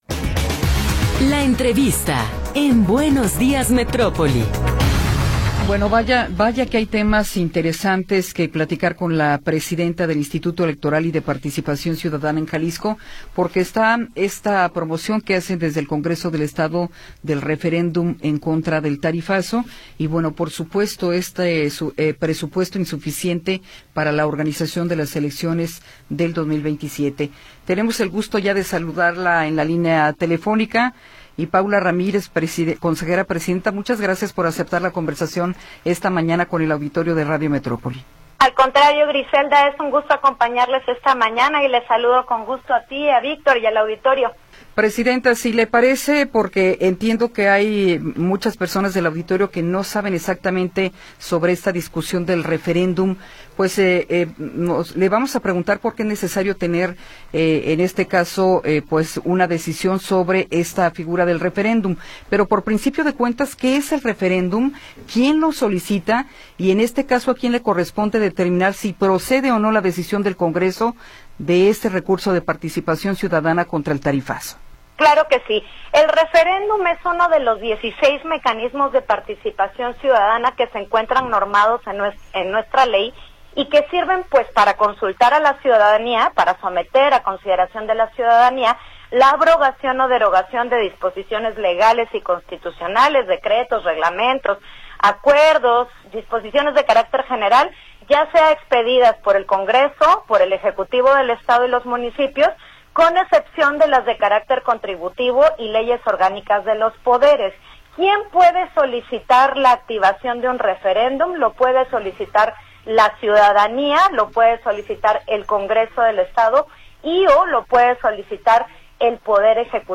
Entrevista con Paula Ramírez Höhne